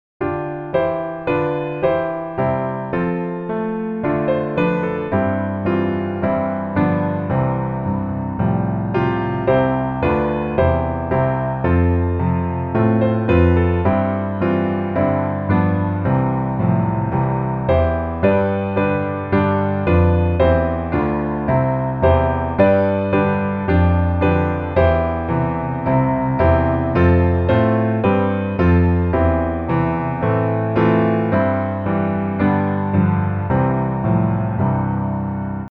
C Major